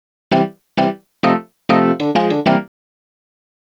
Swinging 60s 2 Organ-C.wav